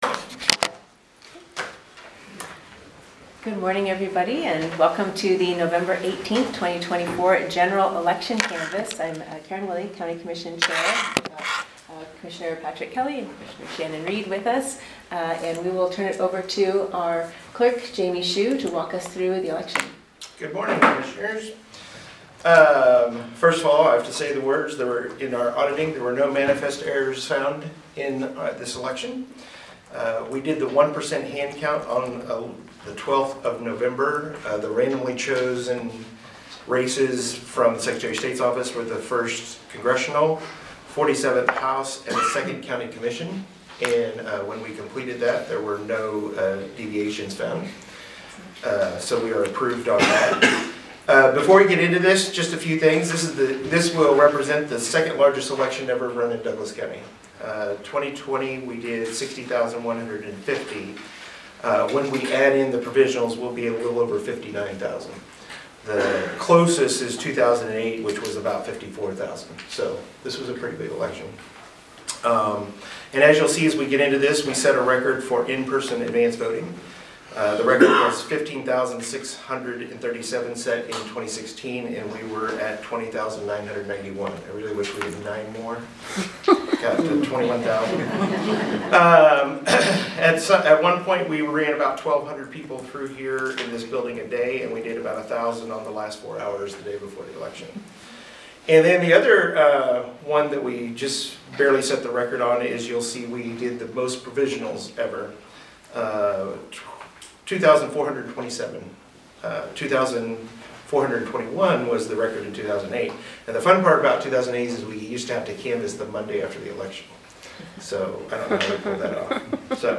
Special County Commission Meeting to canvass the results of the November 5, 2024, General Election.